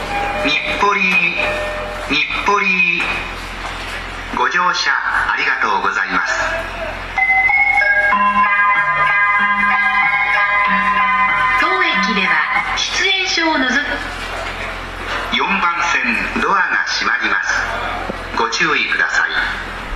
ただ、京成線・新幹線・宇都宮線・高崎線の走行音で発車メロディの収録は困難です。
線路の彼方 発車メロディ